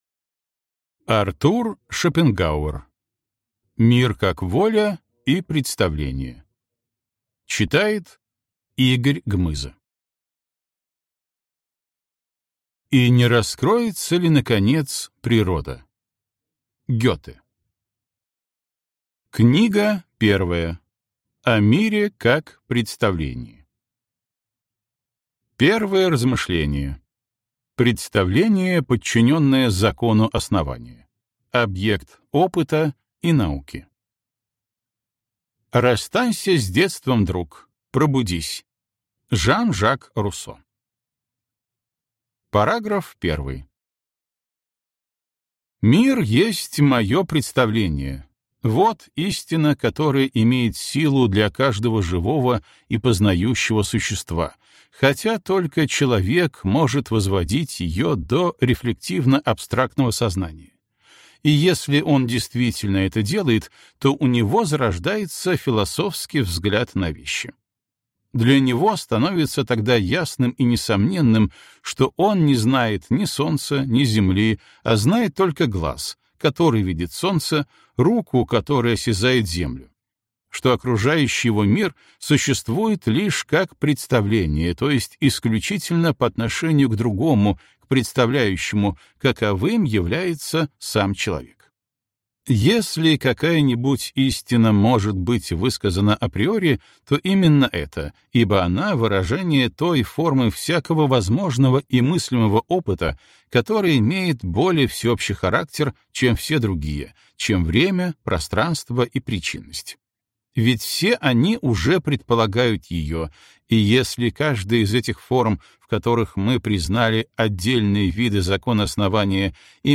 Аудиокнига Книга 1. О мире как представлении | Библиотека аудиокниг